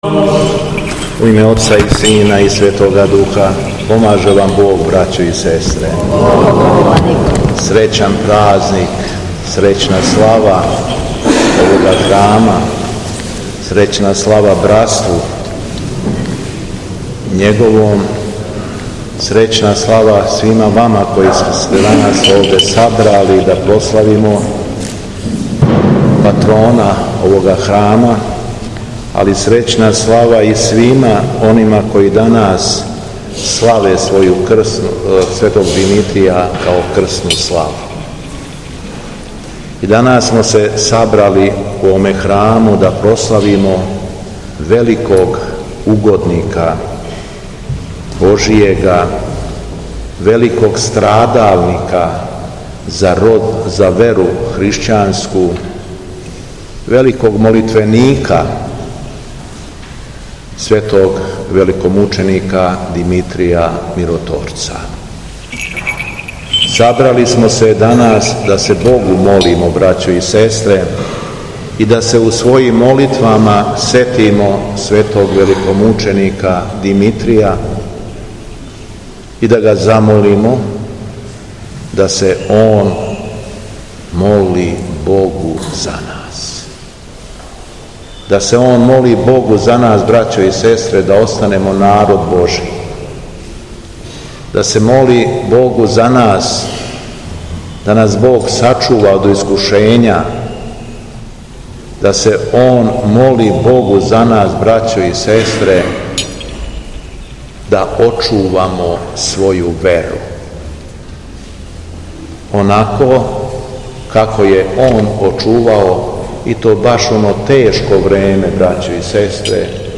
ХРАМОВНА СЛАВА У ЛАЗАРЕВЦУ
Беседа Његовог Преосвештенства Епископа шумадијског г. Јована
У среду, 8. новембра 2023. године, када Црква прославља Светог великомученика Димитрија, свечаном Литургијом у Лазаревцу прослављена је храмовна слава у цркви посвећеној овом светитељу.